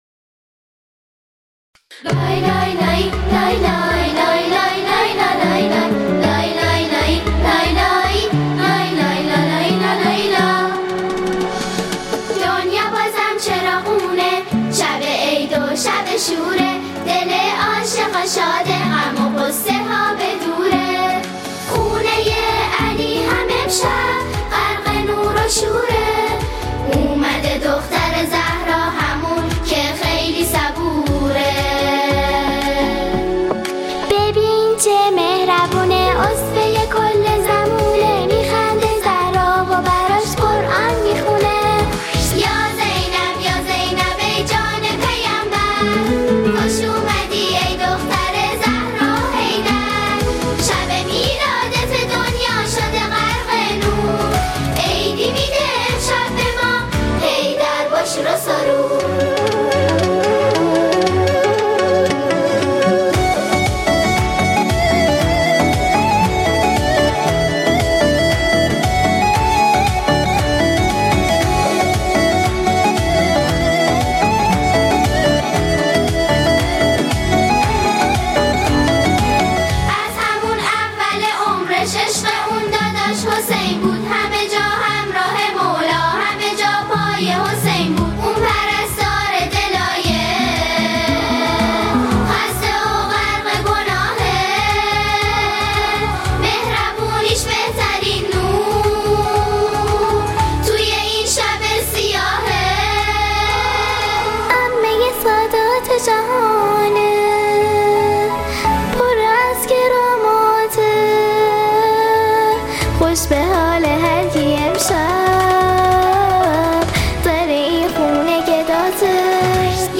ولادت حضرت زینب